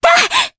Mediana-Vox_Jump_jp_b.wav